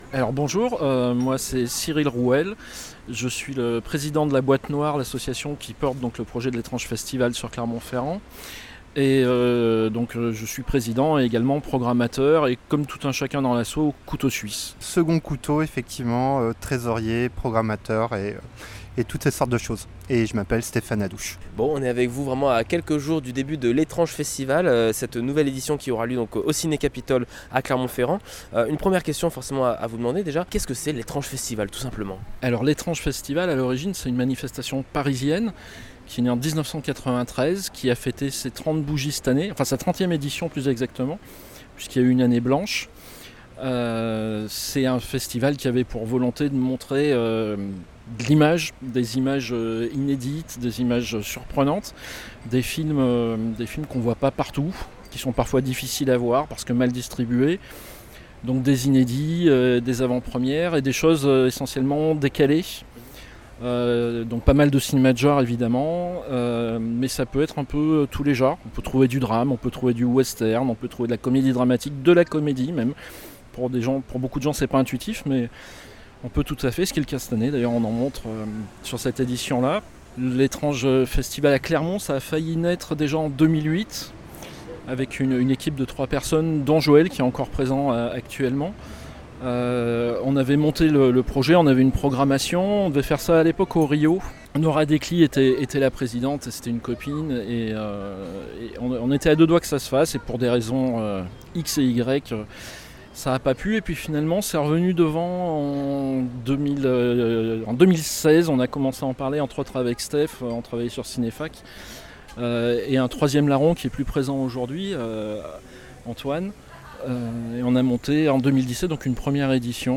Cette semaine, les invités de la rédaction sont les organisateurs de l'Étrange Festival. Le festival de cinéma de genre est de retour du 5 au 12 novembre prochain à Clermont-Ferrand.